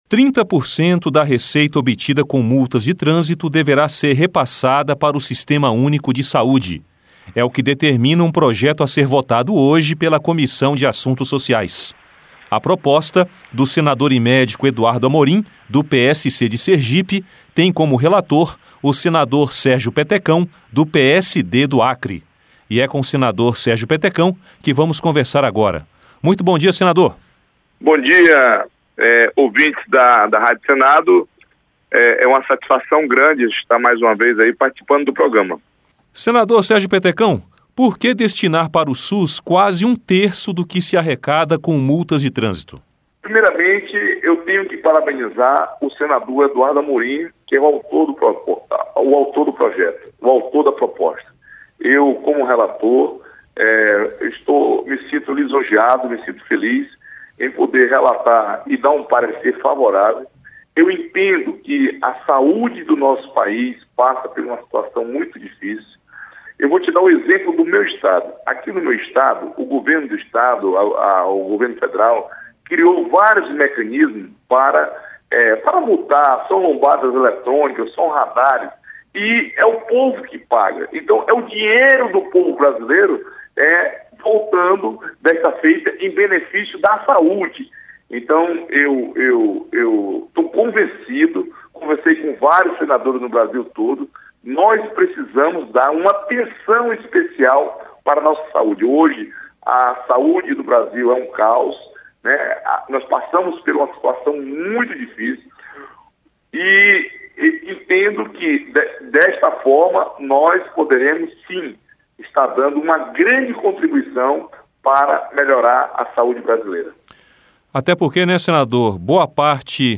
Entrevista com o senador Sérgio Petecão, relator do projeto.